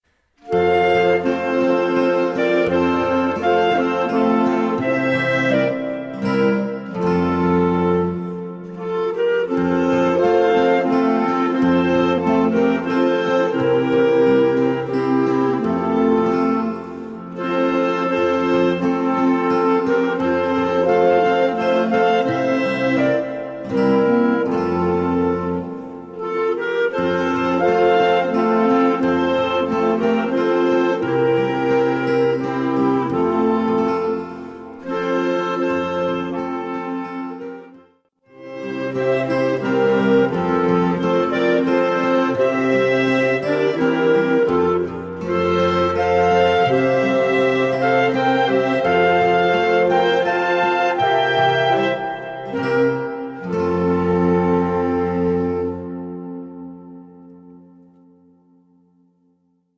Weihnachtsmusik